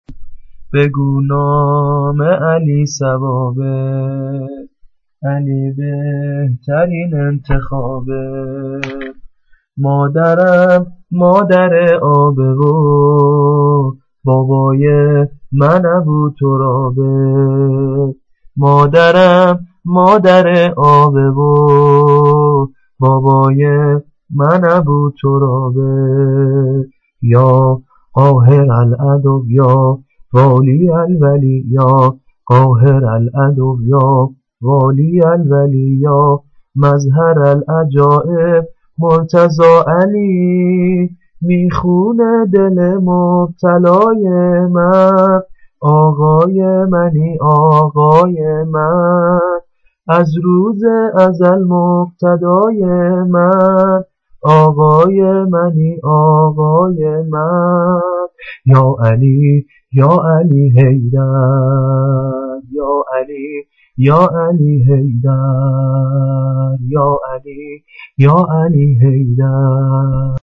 عید غدیر